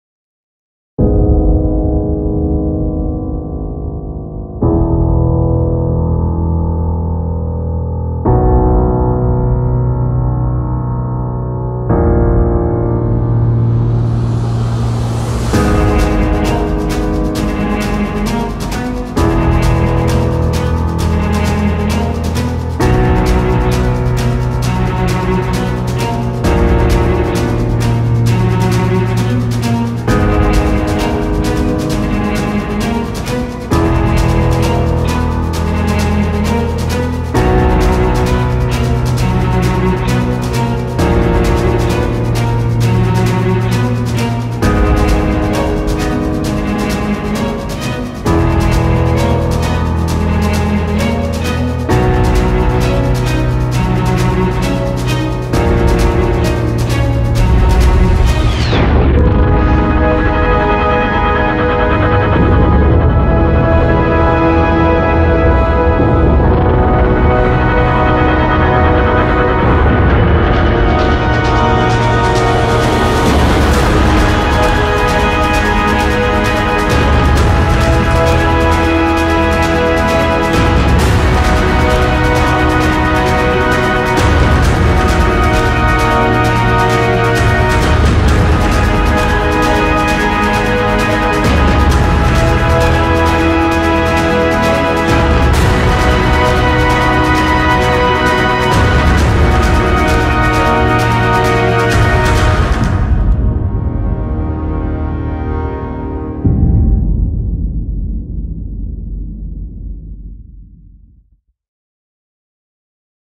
• Качество: 236, Stereo
громкие
мелодичные
спокойные
без слов
таинственные
из игр
загадочные